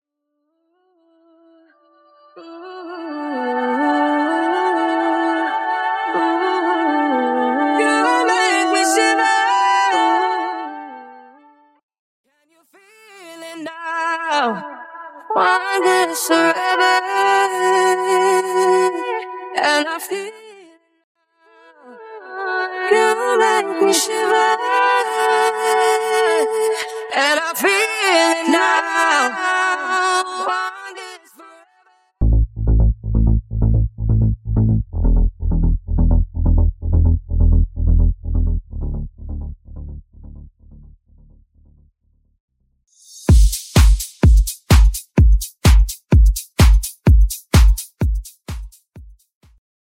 Midline Stem
Percussion & Drums Stem
Subwoofer Stem